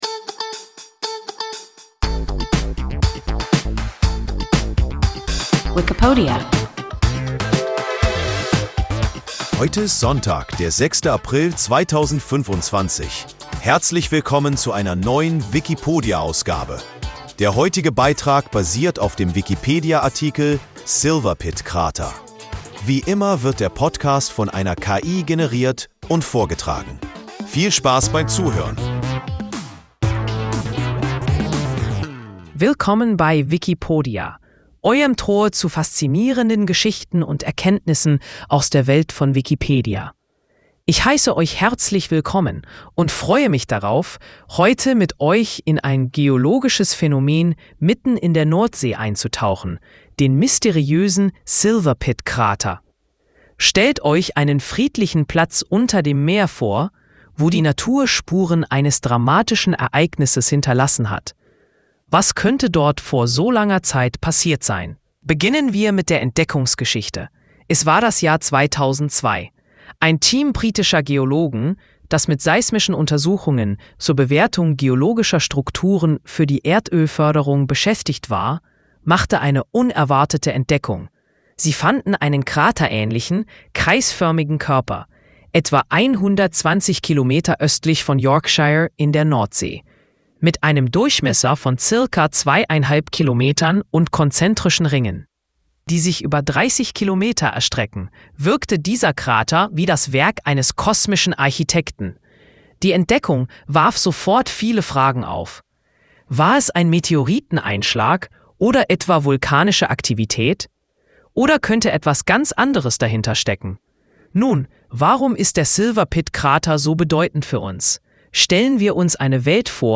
Silverpit-Krater – WIKIPODIA – ein KI Podcast